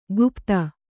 ♪ gupta